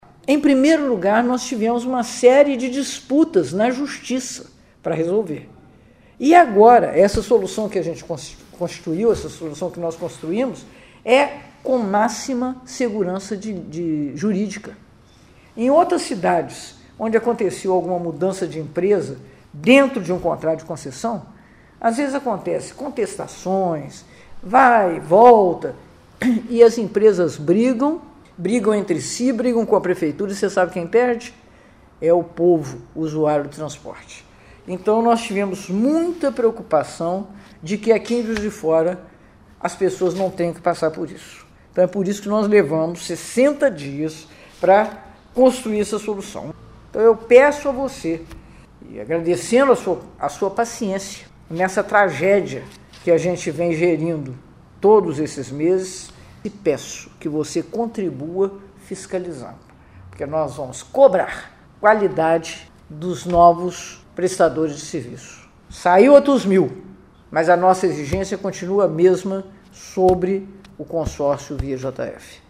O anúncio foi feito nesta sexta-feira, 2, pela prefeita de Juiz de Fora, Margarida Salomão, em vídeo publicado nas redes sociais. Segundo a prefeita, quem vai assumir as linhas é o Consórcio Via JF, composto pela Ansal e Viação São Francisco.
Prefeita Margarida Salomão